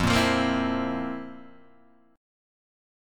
F Major 7th Suspended 4th